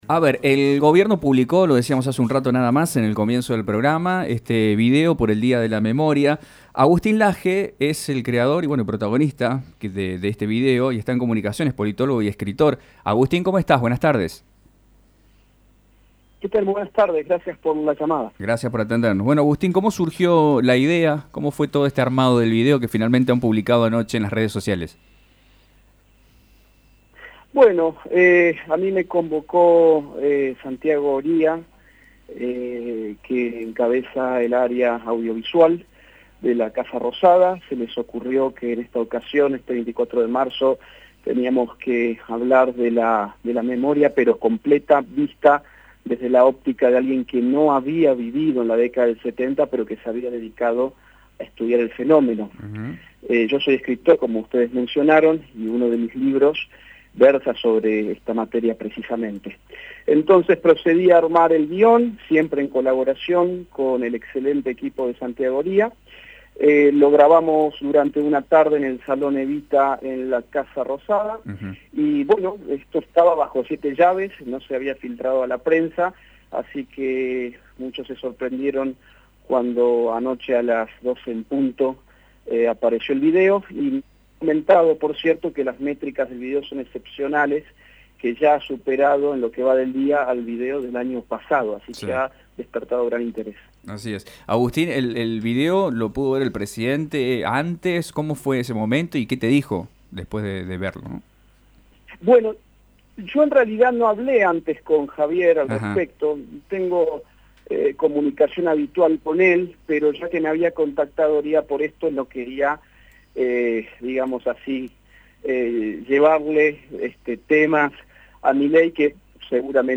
Entrevista a Agustín Laje en Radio Mitre Rosario, realizada el 24 de marzo de 2025.